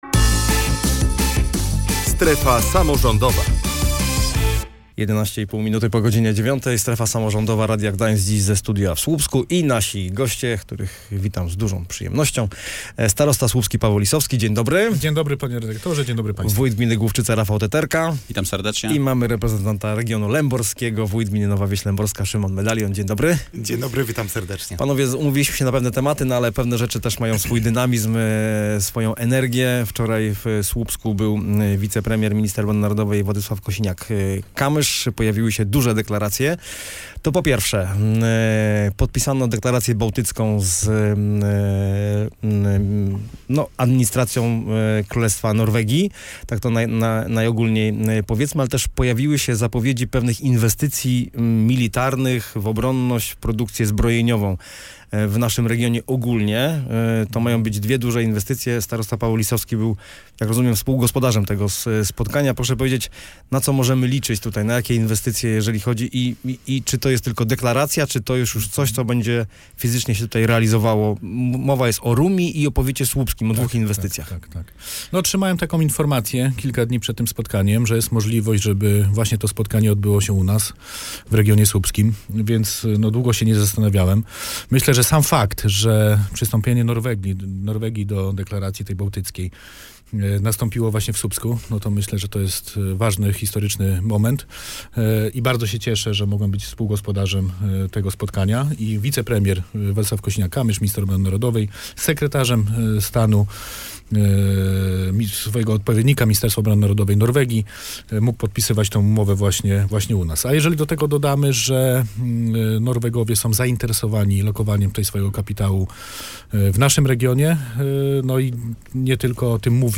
w Studiu w Słupsku byli: Paweł Lisowski, starosta słupski, Rafał Teterka, wójt gminy Główczyce i Szymon Medalion, wójt gminy Nowa Wieś Lęborska.